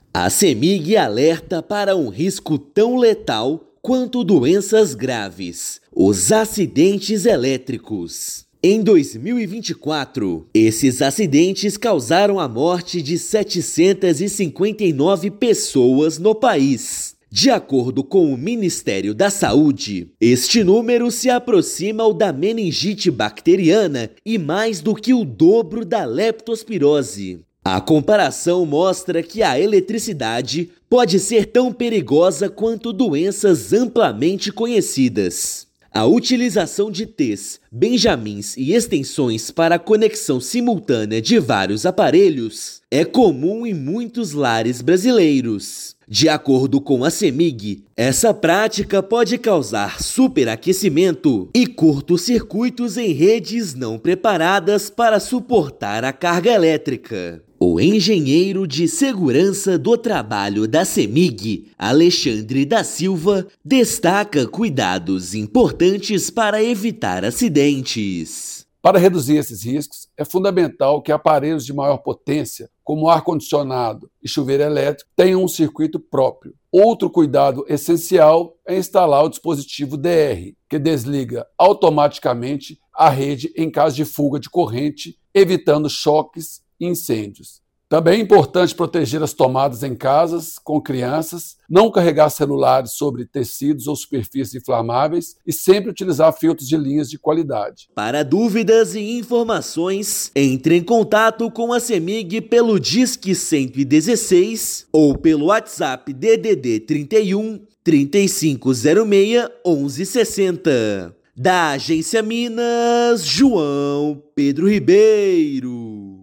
[RÁDIO] Cemig faz alerta para risco de morte em incêndios causados por instalações elétricas precárias
Acidentes provocados por eletricidade matam tanto quanto doenças graves no Brasil. Ouça matéria de rádio.